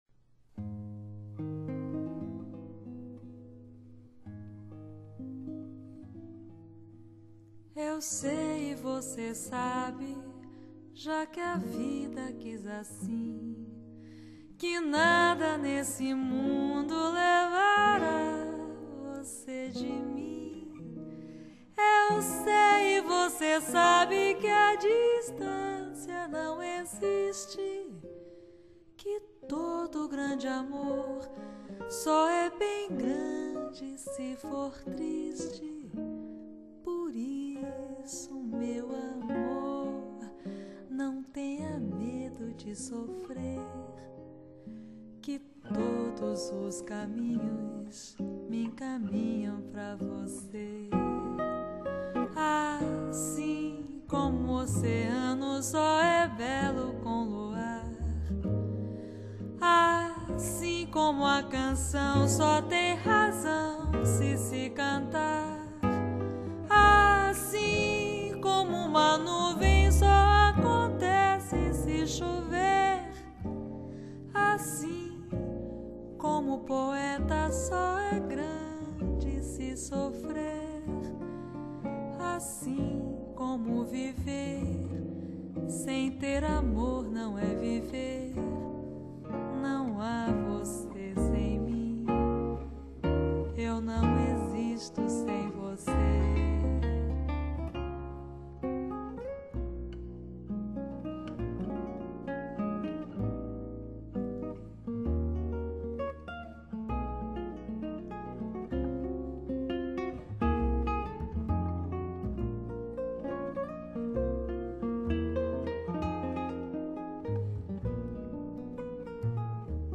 Genre: Jazz/Bossa Nova
以綜合室內樂及新拉丁爵士的樂風來詮釋這些曼妙的歌曲，成就依然光茫四射。
錄音鑑自然真實是本張專輯錄音最大特色。各樂器間音量比例相當平均，高頻不嗆耳，中音區不呆滯，低頻特別豐滿有彈性。